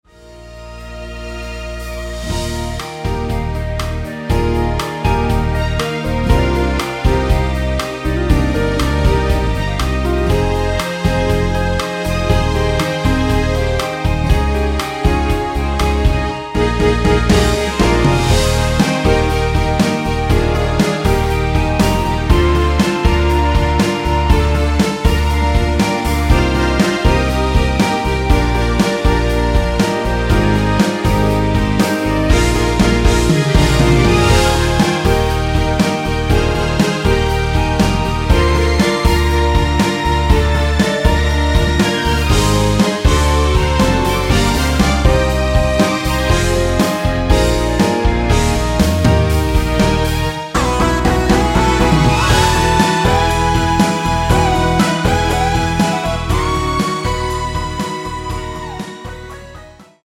원키에서(+4)올린 멜로디 포함된 MR입니다.
Eb
앞부분30초, 뒷부분30초씩 편집해서 올려 드리고 있습니다.
중간에 음이 끈어지고 다시 나오는 이유는
(멜로디 MR)은 가이드 멜로디가 포함된 MR 입니다.